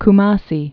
(k-mäsē)